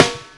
SNARE00000.wav